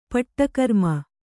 ♪ paṭṭa karma